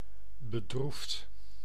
Ääntäminen
Synonyymit désolé gueux navré Ääntäminen France: IPA: /a.fli.ʒe/ Haettu sana löytyi näillä lähdekielillä: ranska Käännös Ääninäyte Adjektiivit 1. bedroefd 2. droef Suku: m .